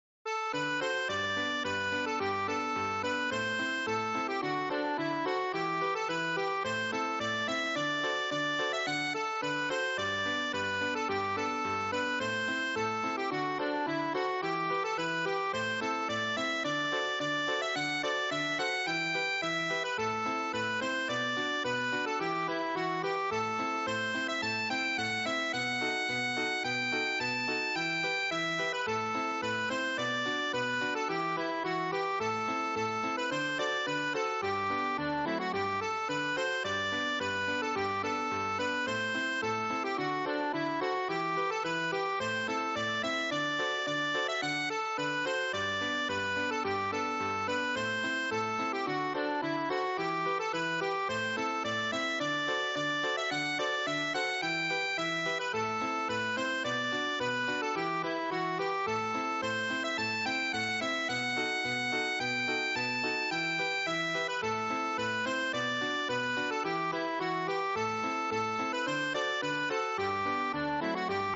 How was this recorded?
midi-mp3